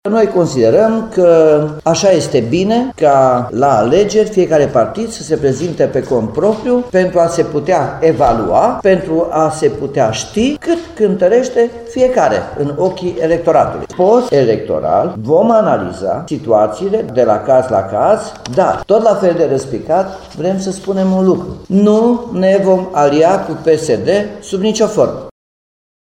Liberalii timişeni nu vor face alianțe preelectorale în judeţ, spune copreședintele formațiunii, Nicoale Robu. Acesta a precizat că indiferent de situaţia de după alegeri, nu se vor face alianţe cu PSD, chiar dacă, alte înţelegeri pot avea loc:
01.-Nicolae-Robu-PNL-Timis.mp3